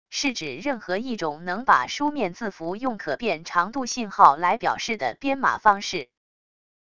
是指任何一种能把书面字符用可变长度信号来表示的编码方式wav音频